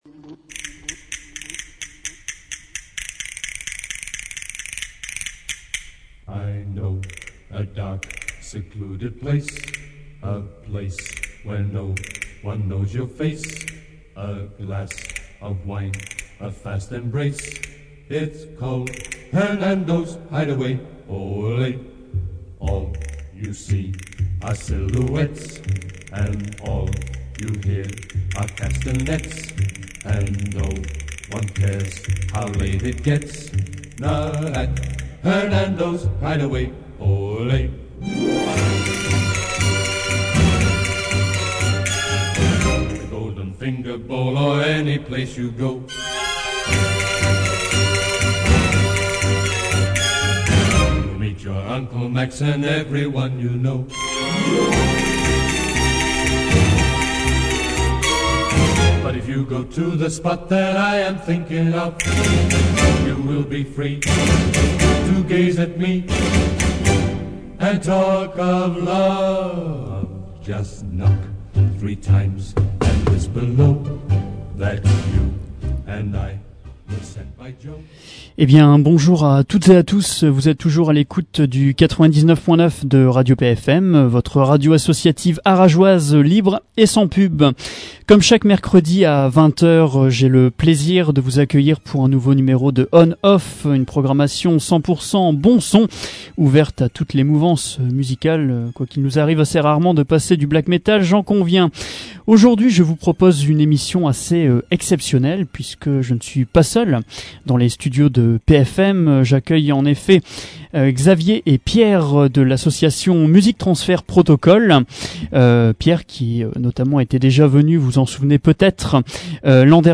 étaient de passage dans les studios de radio PFM